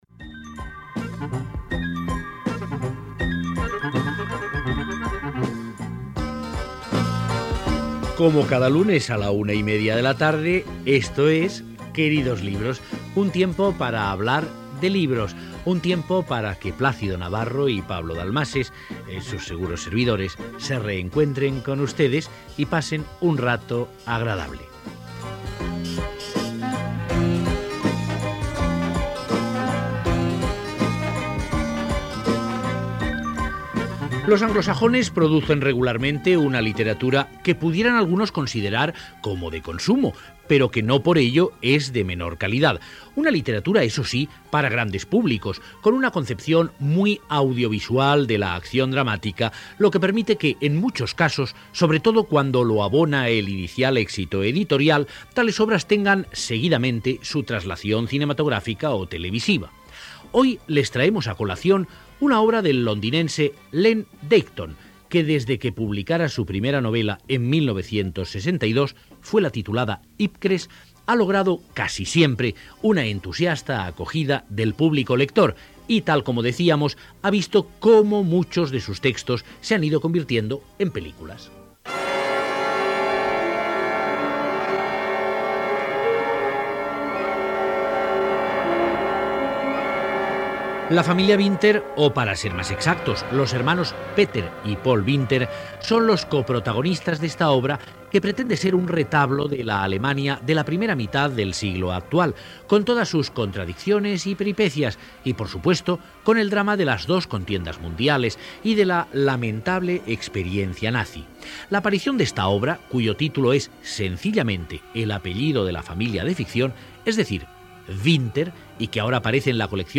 34d91e56ae49ef030f5347e759c6db6b67dccf3a.mp3 Títol Radio 5 Barcelona Emissora Radio 5 Barcelona Cadena RNE Titularitat Pública estatal Nom programa Queridos libros Descripció Presentació del programa, equip, la literatura anglosaxona, presentació de l'obra "Winter" de Len Deighton. Adaptació radiofònica d'un fragment del llibre.